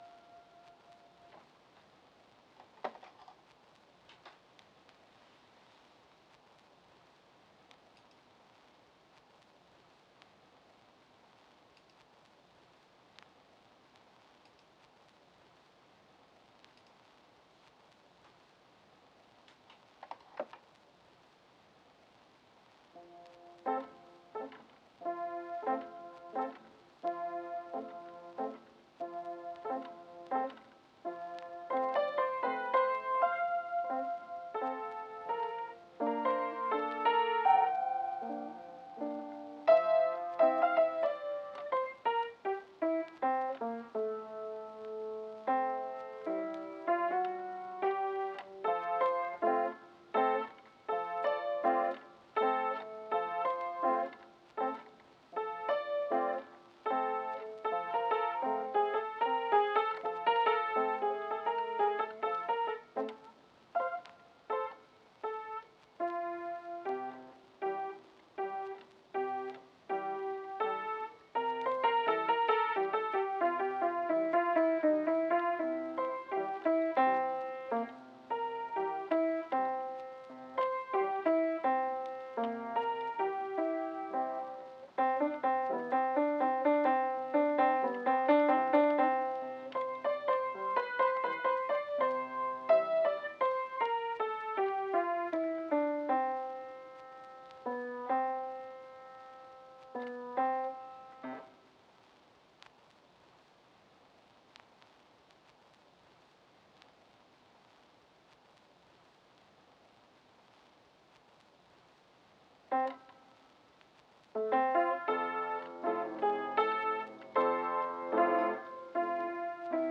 This piano piece was improvised on an upright piano in a room with bare floorboards, based on research undertaken at the British Library re: domestic piano recordings from the period of time in which the film was originally made. The recording was then treated and processed to resemble the sounds of recording and playback technology from the same era.